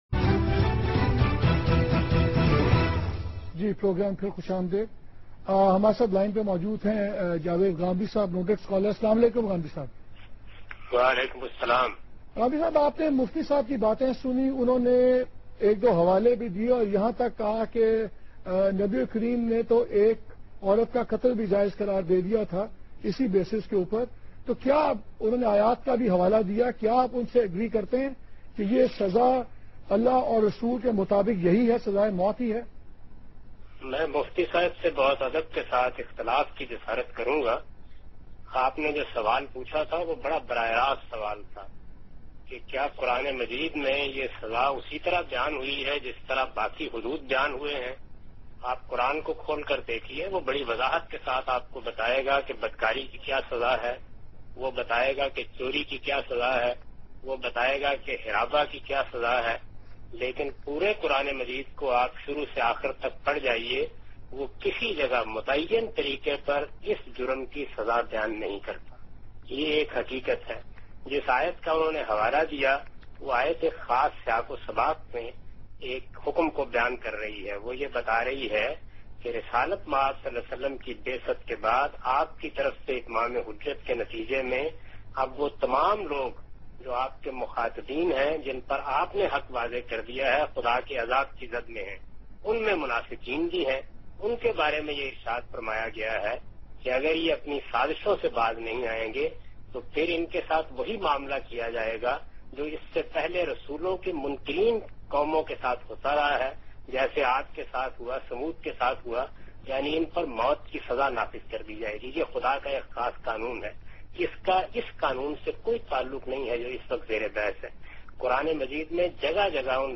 Javed Ahmad Ghamidi is talking about Blasphemy Law in Pakistan on Geo Tv with Mufti Munib.